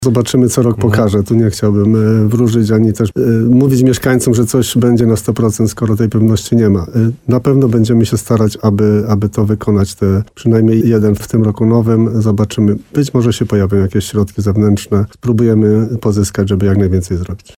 Jak mówi wójt Rytra Jan Kotarba, wszystko zależy właśnie od możliwości finansowych.